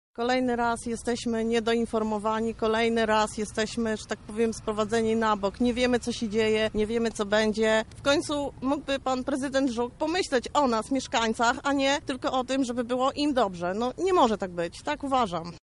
mówi jedna z mieszkanek